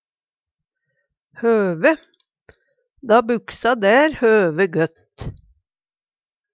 høve - Numedalsmål (en-US)